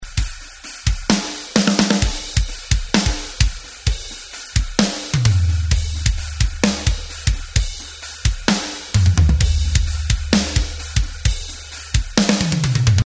Snare Drum - Mixing
Hallo ich hab mal ein Test Mp3 hochgeladen mit meinem Drum. Dazu die Frage, bei mir klingt die Snare wie ein Blechkübel :( Ich hätte sie gerne wie in dem Beispielvideo, ist die gleiche Library die ich benutzt habe.